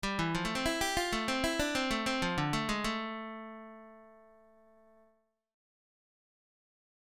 Blues lick > Lick 5